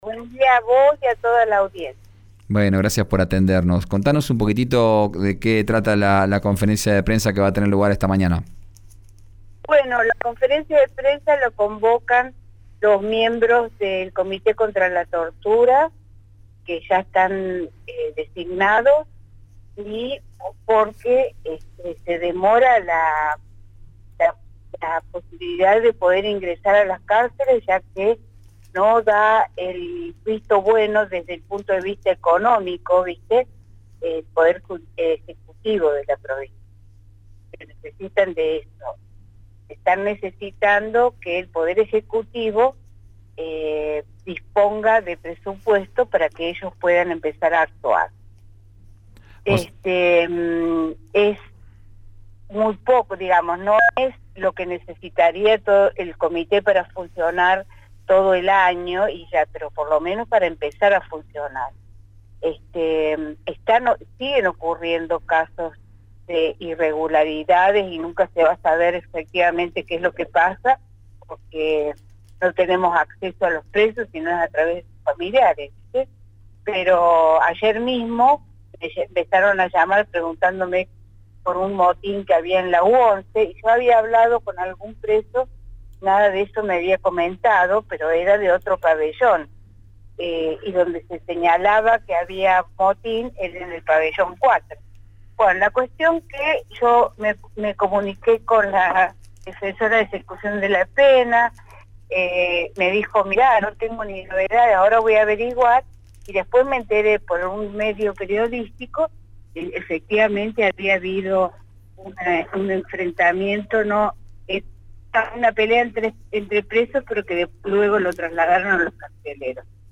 en RÍO NEGRO RADIO